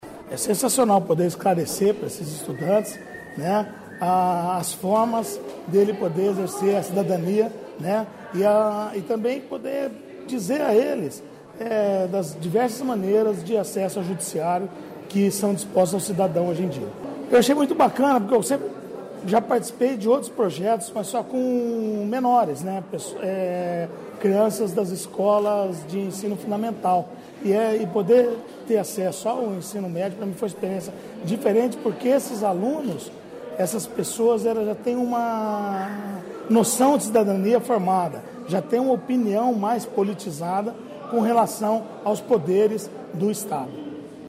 O juiz Fernando Ganem, representante do Tribunal de Justiça do Paraná, destacou a interação promovida pelo programa e ressaltou a importância dos jovens conhecerem as instituições e serviços que tem a disposição. Confira a entrevista.